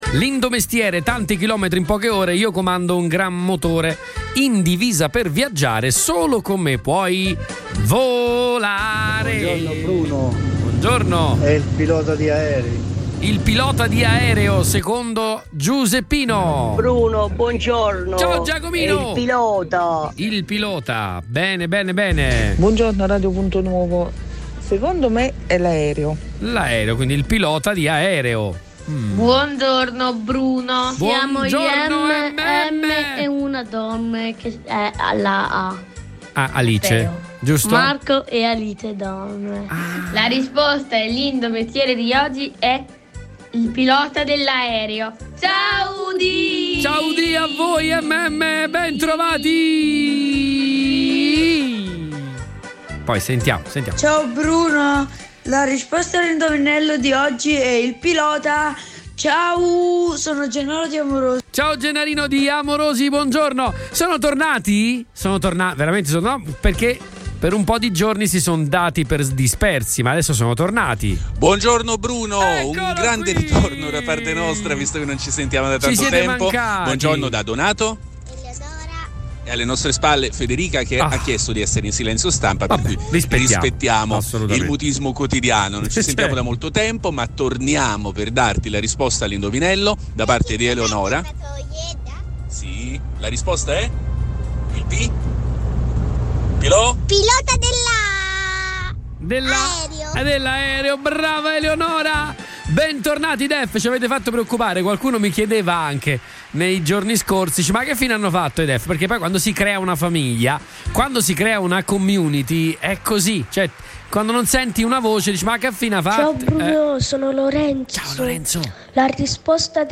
ECCO LE RISPOSTE DEGLI ASCOLTATORI